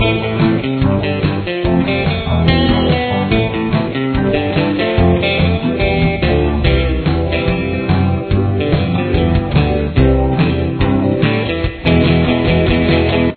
Tempo: 143 beats per minute
Key Signature: A minor